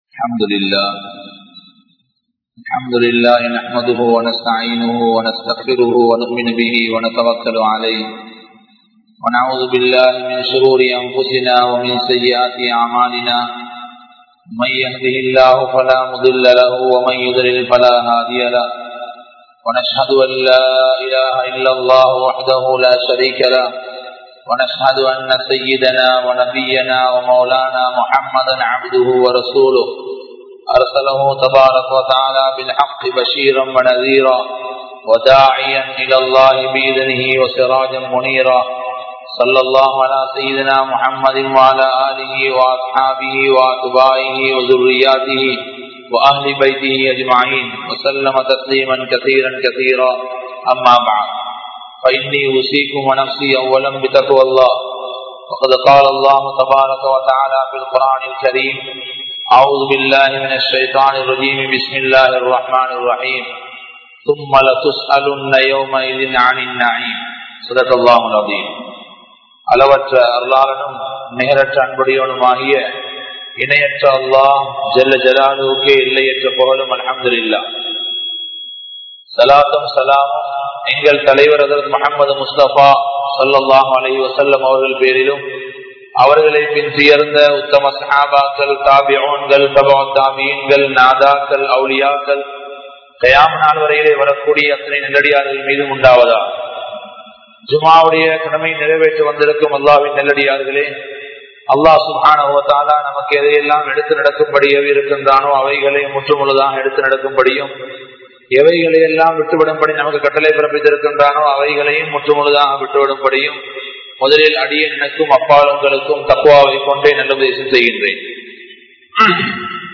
Are We True Muslims? | Audio Bayans | All Ceylon Muslim Youth Community | Addalaichenai